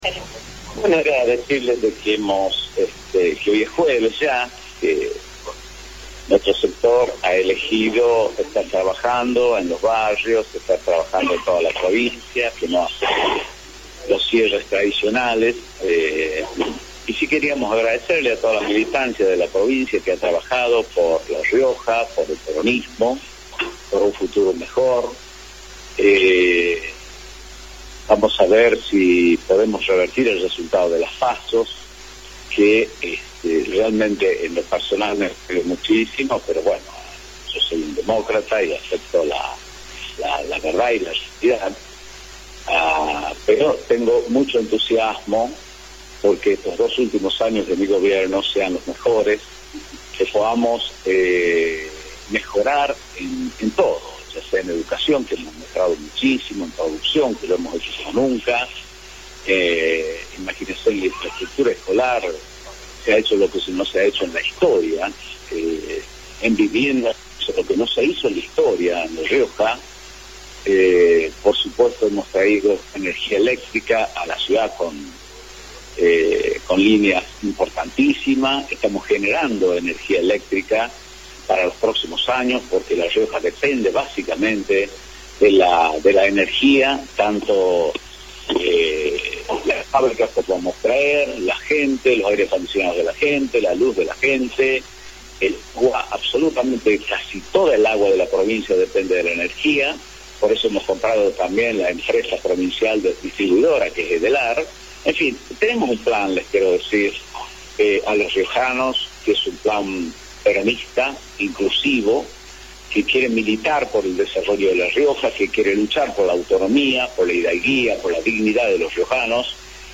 El gobernador Luis Beder Herrera sorprendió este jueves con una rueda de prensa en la Residencia Oficial. En la oportunidad, anticipó el resultado del domingo: “será 1 y 1”.
El audio de la conferencia de prensa